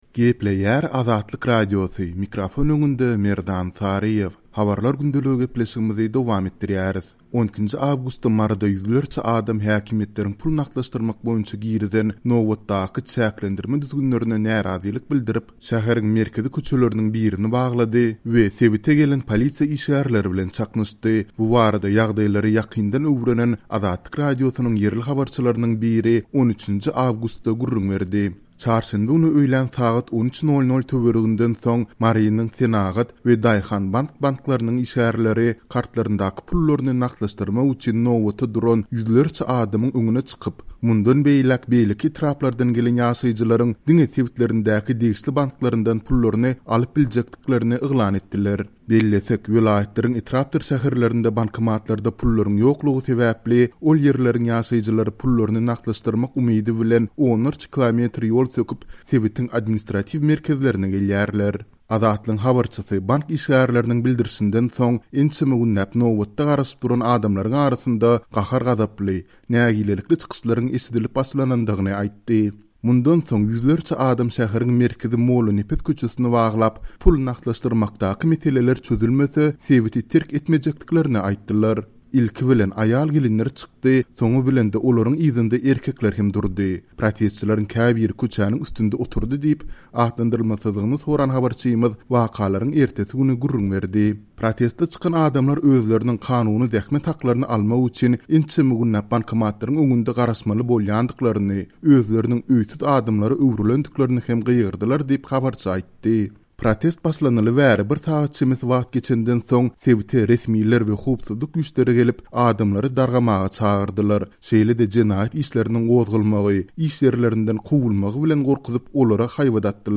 12-nji awgustda Maryda ýüzlerçe adam, häkimiýetleriň pul nagtlaşdyrmak boýunça girizen nobatdaky çäklendirme-düzgünlerine närazylyk bildirip, şäheriň merkezi köçeleriniň birini baglady we sebite gelen polisiýa işgärleri bilen çaknyşdy. Bu barada ýagdaýlary ýakyndan öwrenen Azatlyk Radiosynyň ýerli habarçylarynyň biri 13-nji awgustda gürrüň berdi.